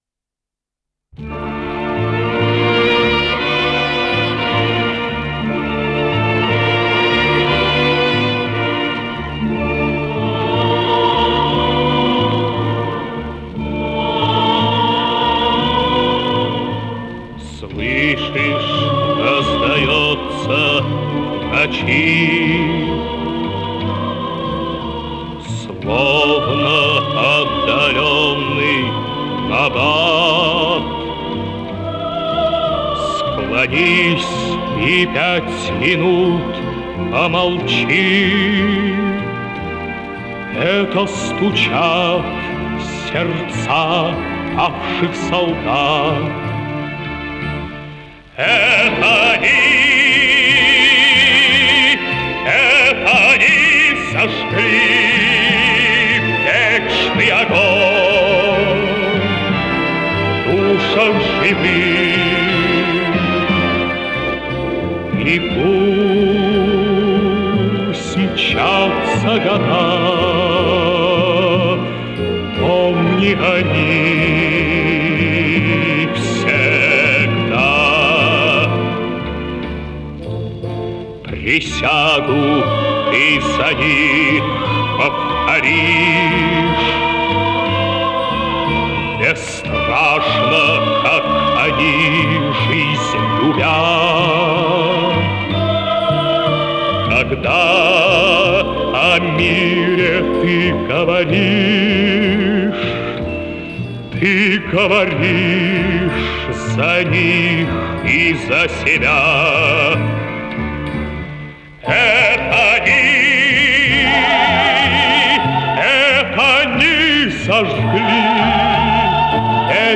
Скорость ленты38 см/с
ВариантМоно